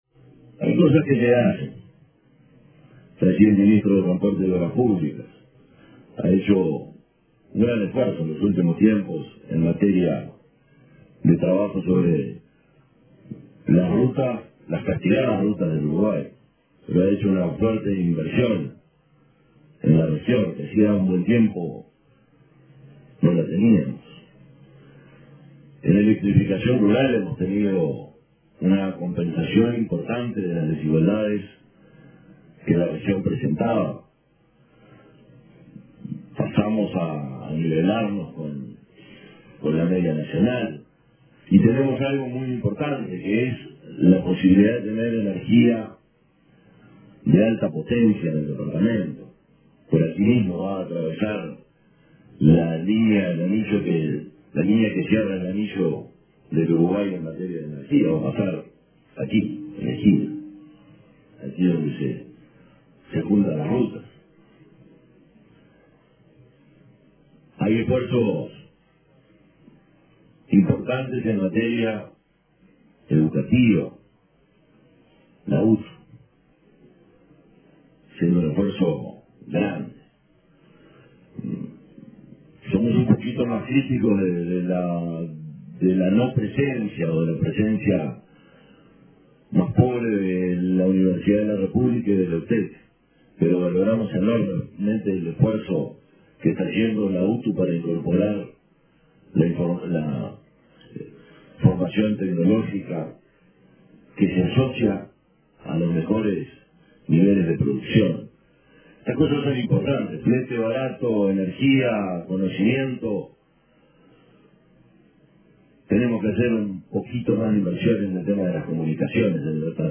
El intendente de Cerro Largo, Sergio Botana, enumeró, durante el Consejo de Ministros en Ramón Trigo, varias de las obras que el gobierno nacional está realizando en el departamento y resaltó el trabajo conjunto con las autoridades nacionales en una actitud “de construcción”. Resaltó las obras viales, los buenos indicadores de seguridad del departamento, la electrificación rural y la educación en UTU, entre otros temas.